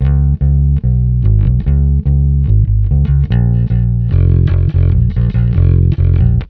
Twisting 2Nite 3 Bass-C.wav